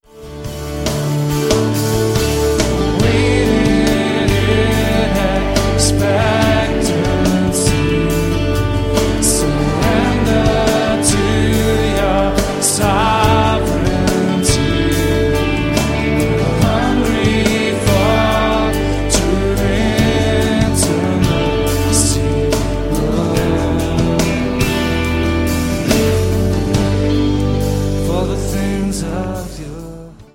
Diese Live-CD entfacht ein kreatives
• Sachgebiet: Praise & Worship